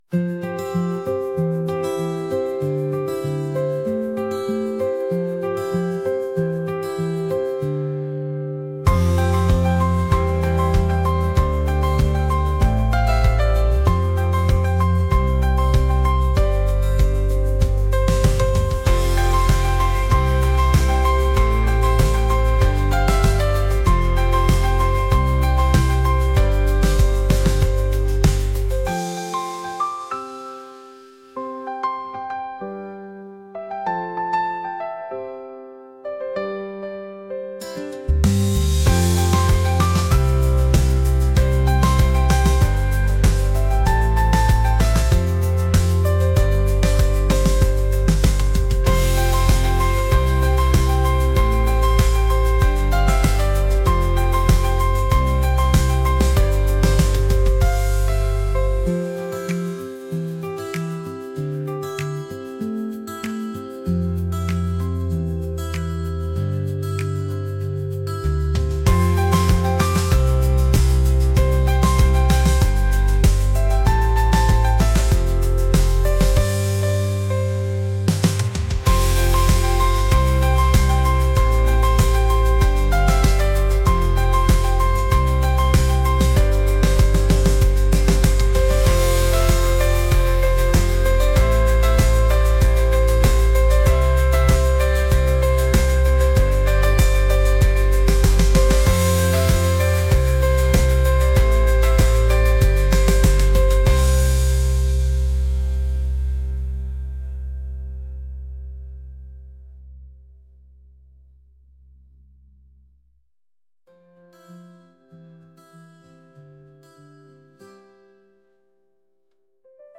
acoustic | pop